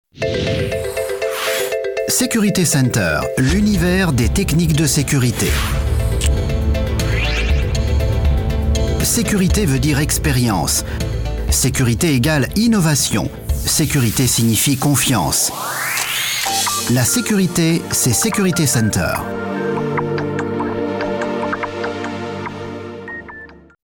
Sprecher französisch.
Kein Dialekt
Sprechprobe: Werbung (Muttersprache):
french voice over artist